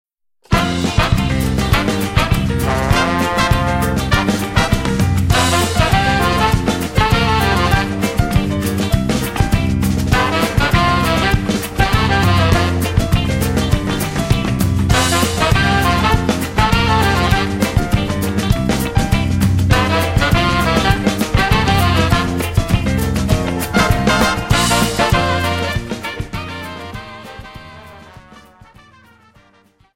SAMBA